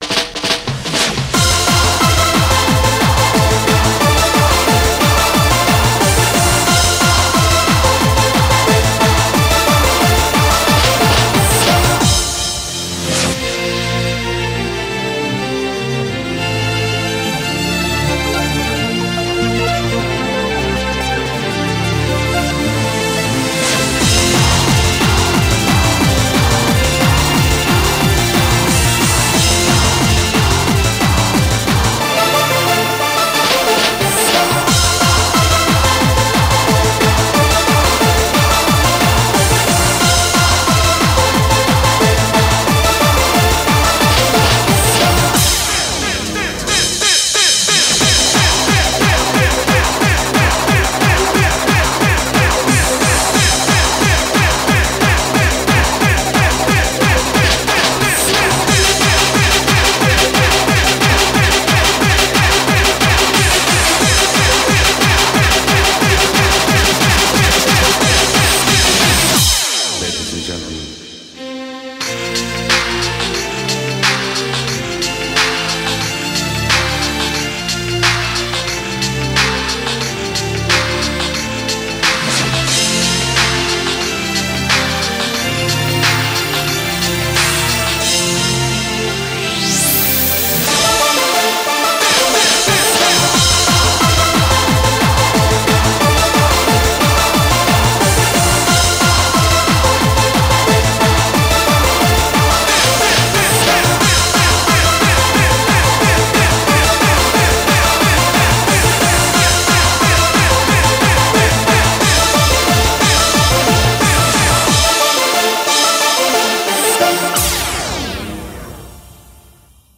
BPM90-180
Comments[HAPPY HARDCORE]